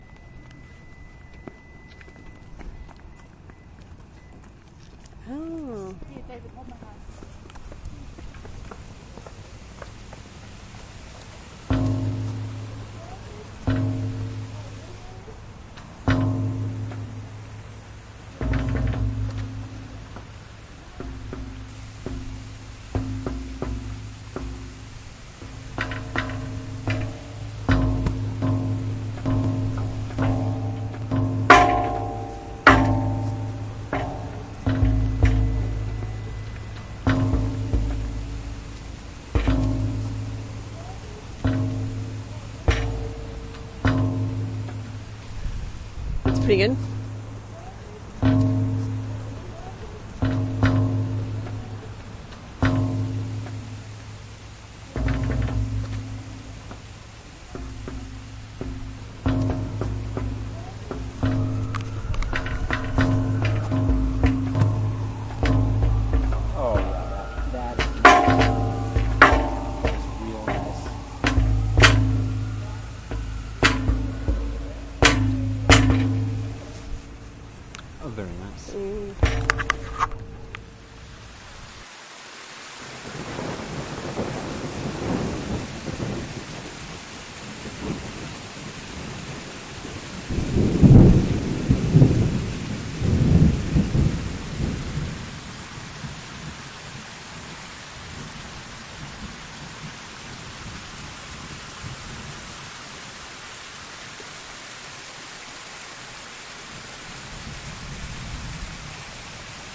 停车库FX/Roomtone " 停车库金属门铰链吱吱作响
描述：我的金属门FX套装的孤立铰链吱吱声，在一个停车场内。
标签： 停车场 车库 铰链 金属
声道立体声